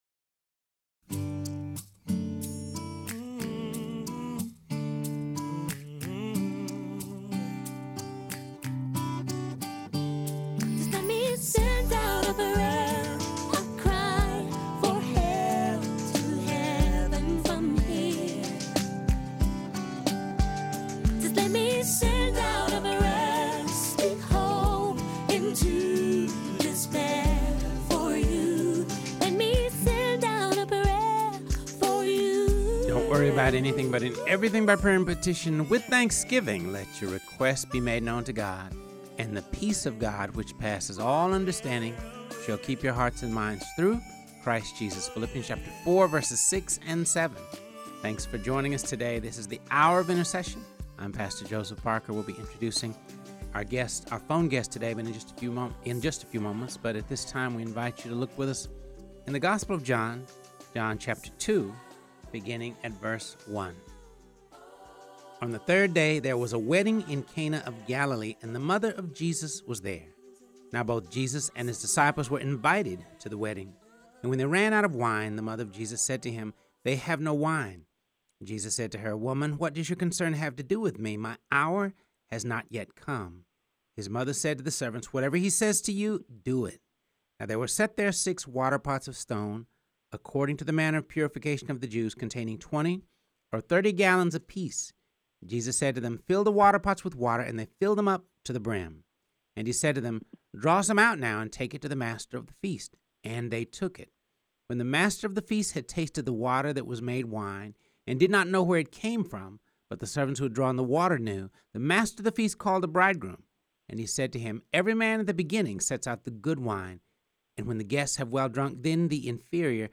joined via phone